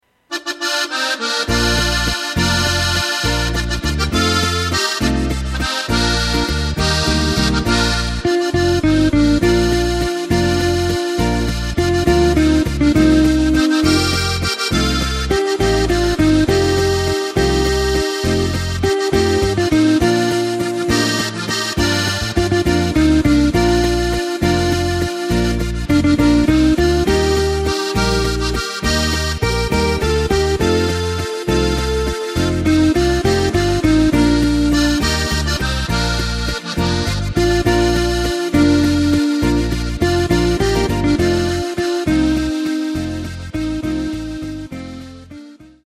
Takt:          3/4
Tempo:         204.00
Tonart:            Bb
Walzer aus dem Jahr 2016!